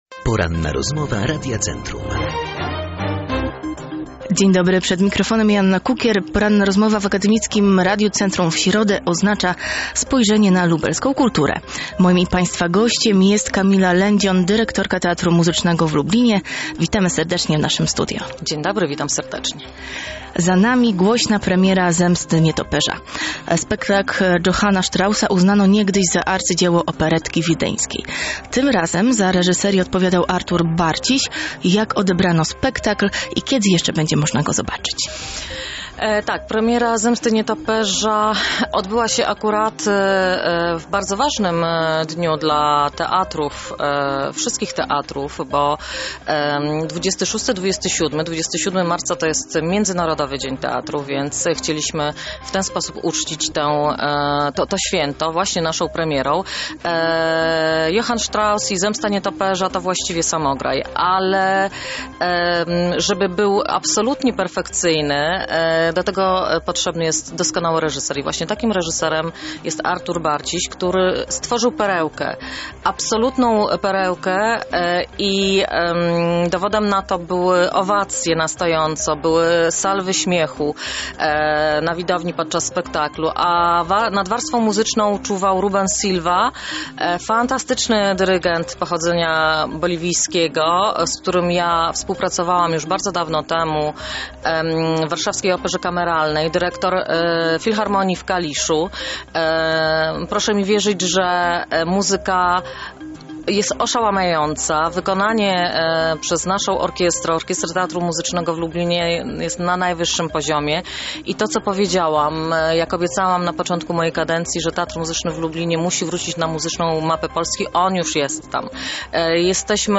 cała rozmowa
ROZMOWA-6.mp3